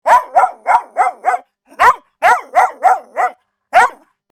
Free Dog Bark Bouton sonore